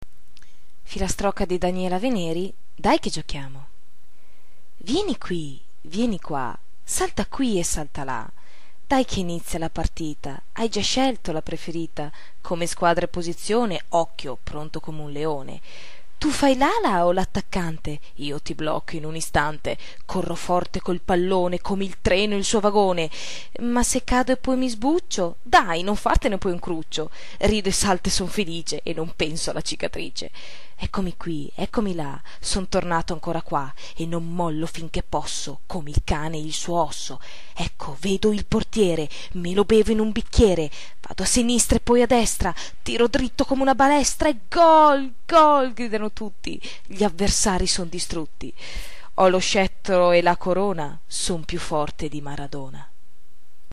Poesie filastrocche, Poesie recitate da artisti